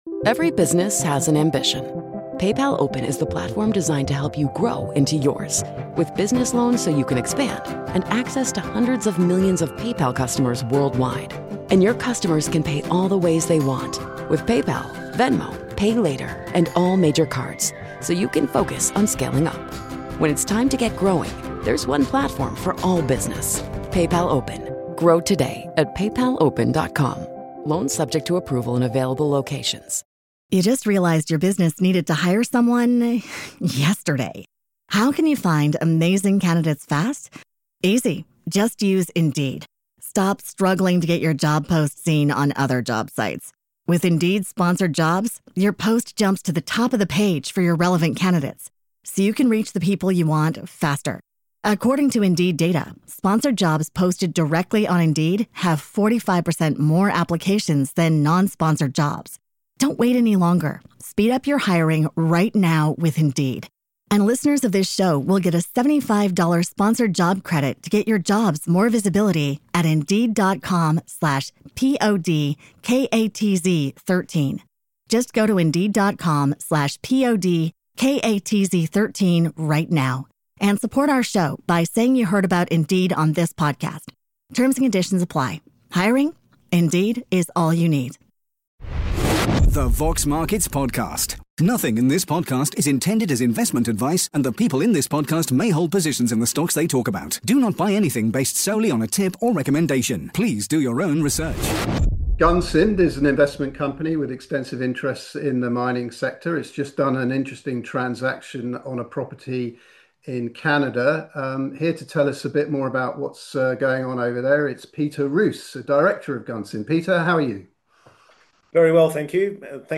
The Vox Markets Podcast / Q&A